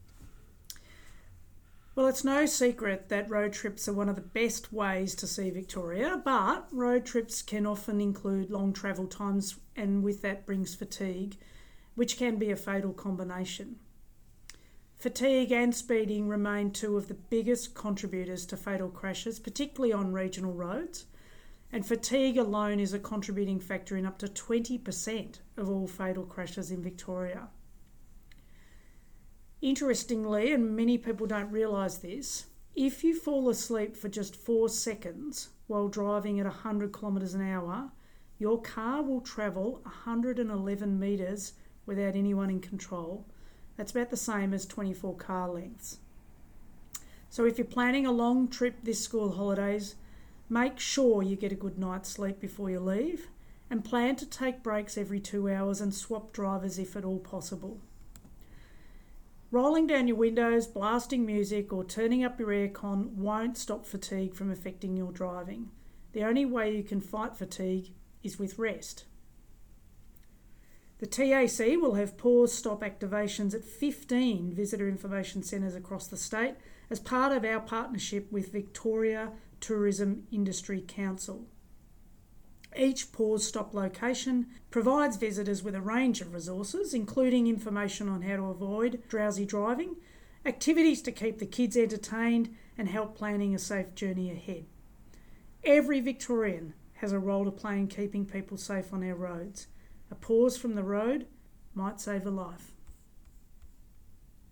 Audiofile interview